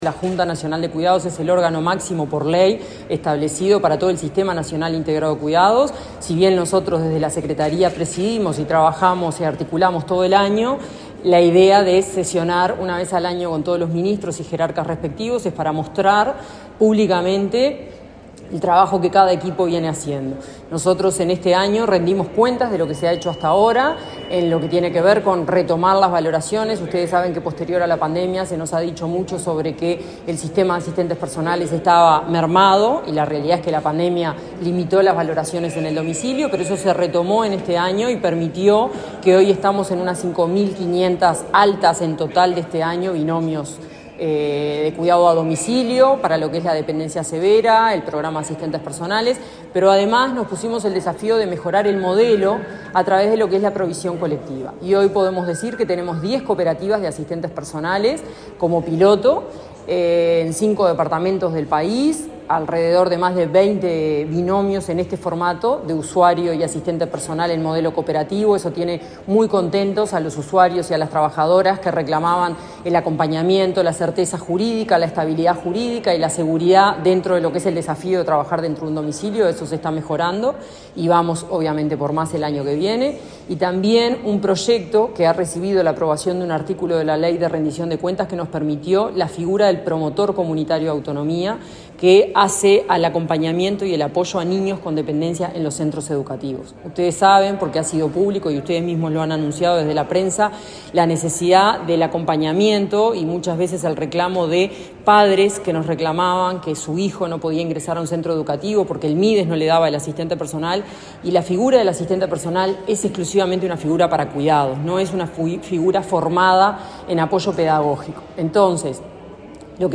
Declaraciones de la directora nacional de Cuidados, Florencia Krall
Este martes 29 en el Palacio Legislativo, la directora nacional de Cuidados del Mides, dialogó con la prensa luego de participar de la sesión de la